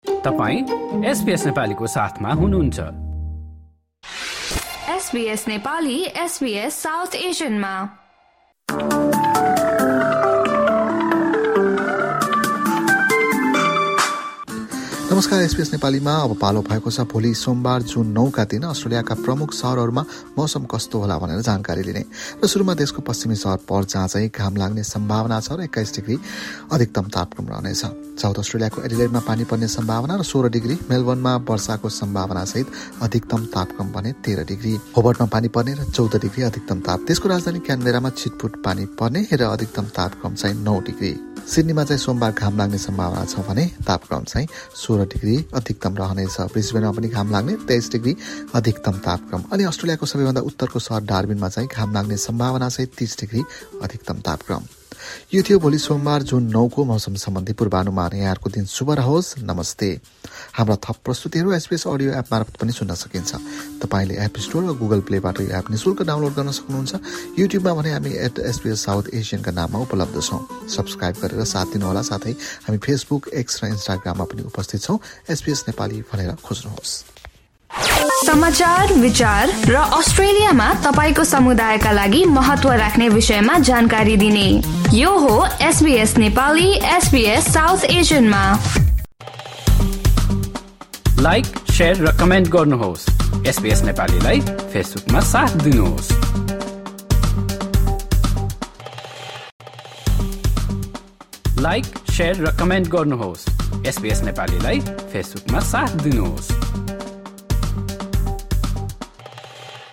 Australian weather update for Monday, 9 June 2025 in Nepali.